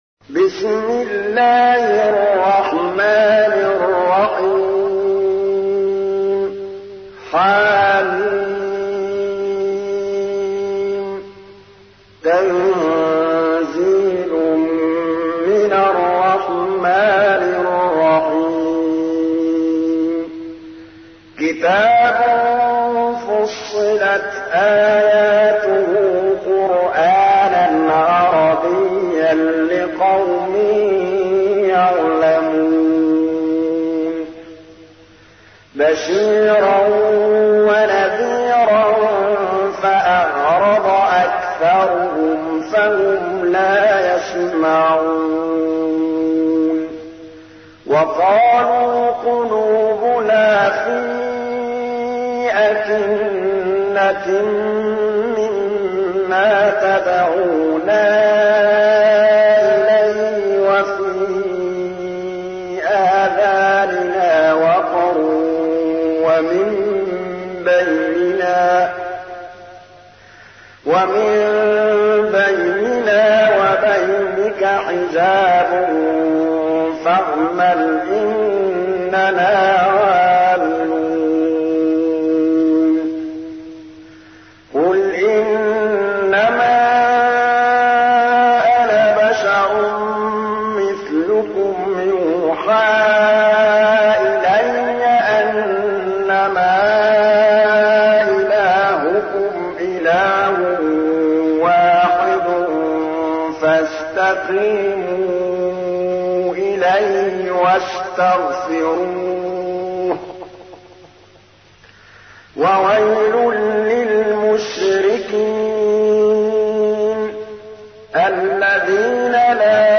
تحميل : 41. سورة فصلت / القارئ محمود الطبلاوي / القرآن الكريم / موقع يا حسين